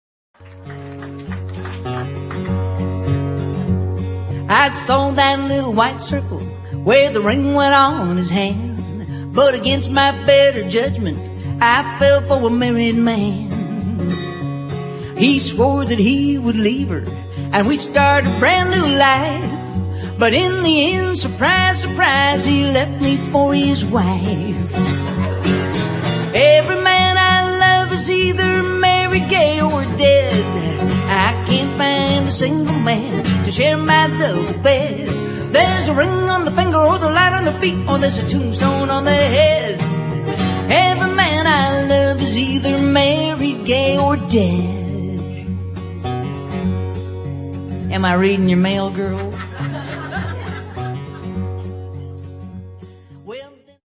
(CD $12.95) - live comedy music and standup